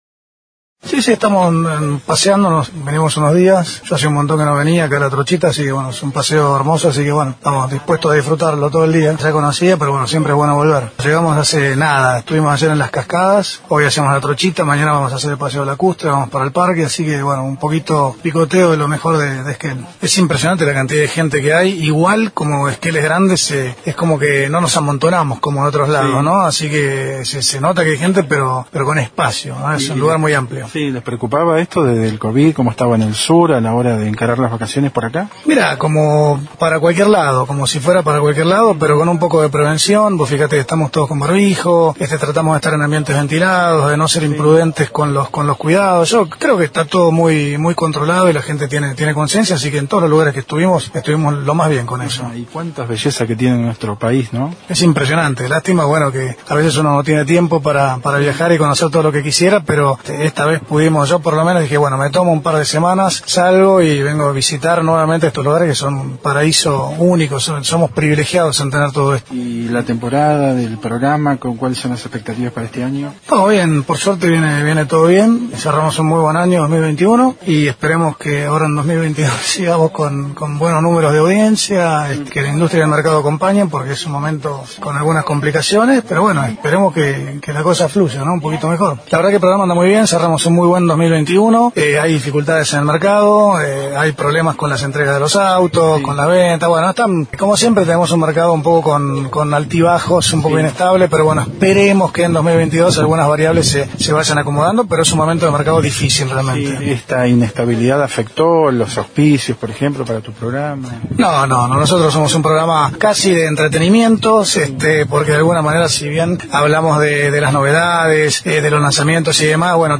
Junto a su familia se encuentra de vacaciones disfrutando de Esquel y la zona. En diálogo con Noticias de Esquel en la estación de La Trochita, Antico habló de su descanso en familia, de las bellezas del país y nuestra zona, pero además de su trabajo en televisión con el programa sobre automóviles más visto del país.